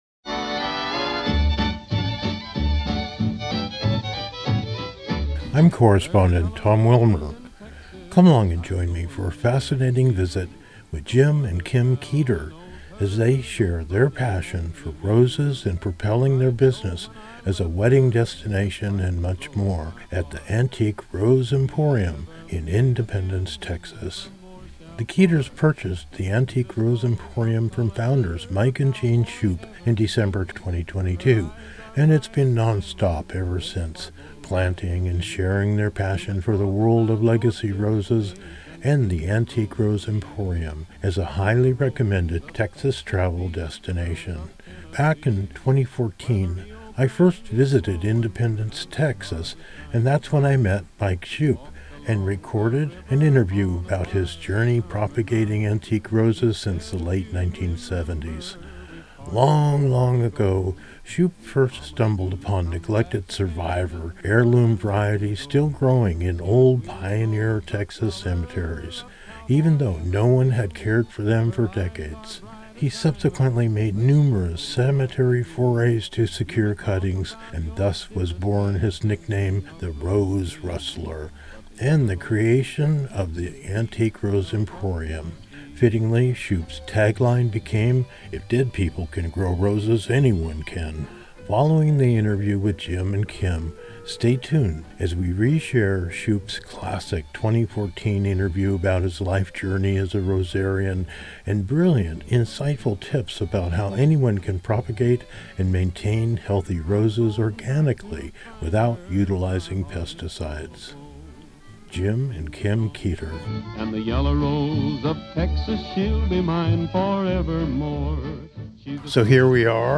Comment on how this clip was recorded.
recorded live on location across America and around the world